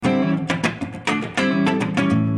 Funk guitars soundbank 1
Guitare loop - funk 29